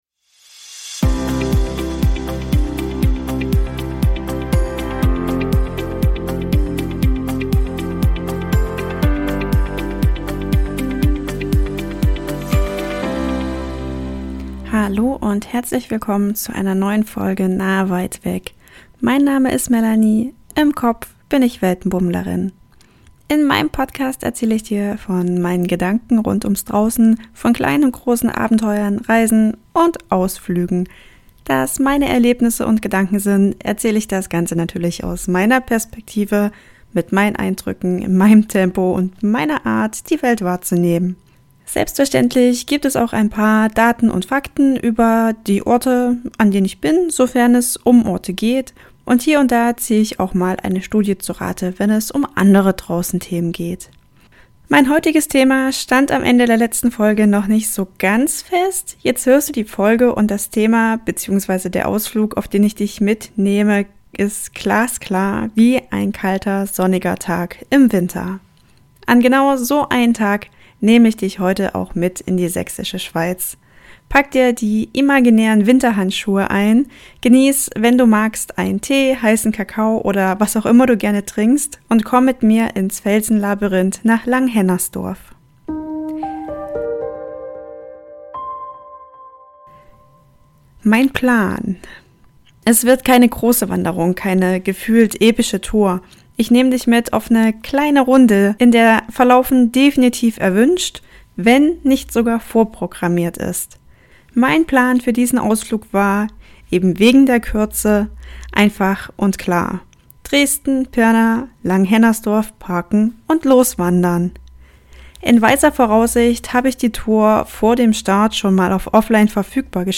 Tipp: Diese Folge wirkt besonders gut mit Kopfhörern – und ein bisschen Zeit.